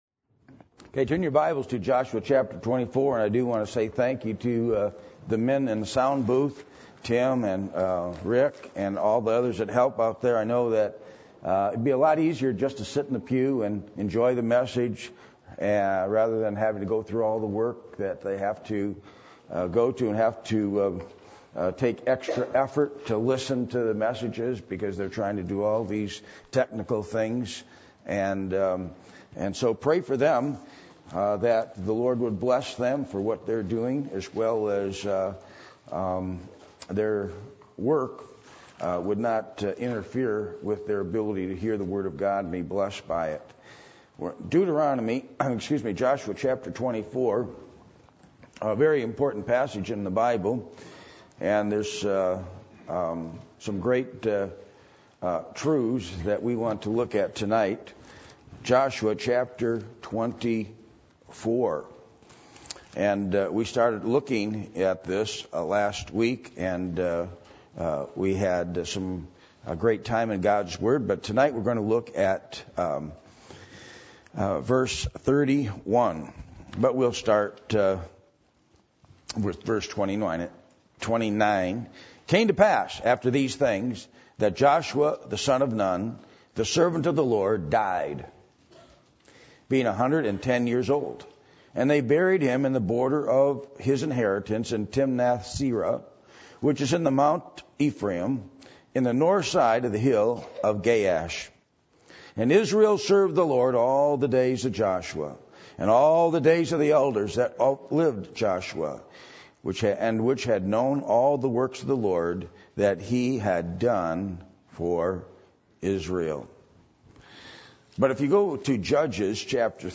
Passage: Joshua 24:29-33, Judges 2:7-10 Service Type: Sunday Evening %todo_render% « The Thief On The Cross